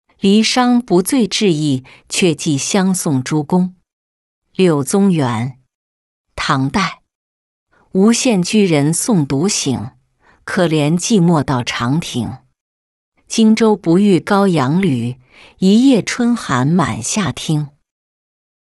离觞不醉至驿却寄相送诸公-音频朗读